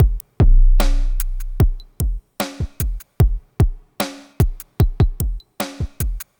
Downtempo 12.wav